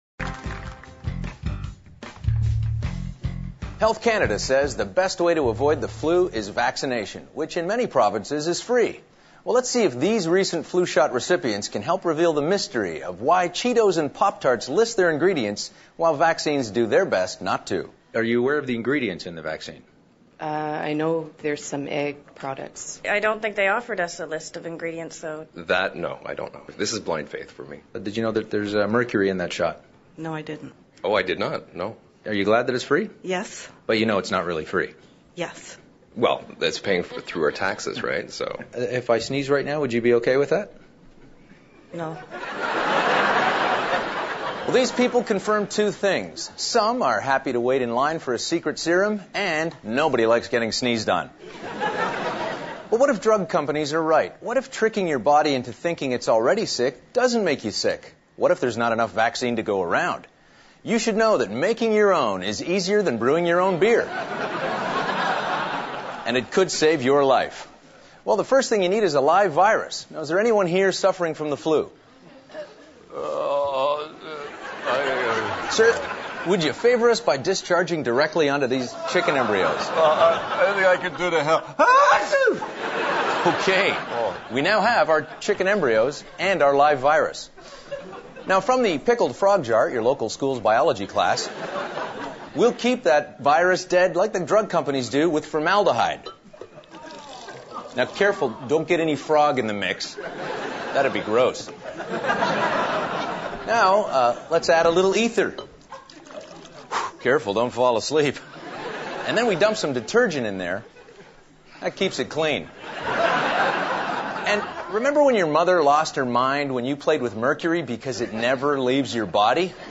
This is a sketch that describes the ingrediants you find in the H1N1 Swine flu vaccine.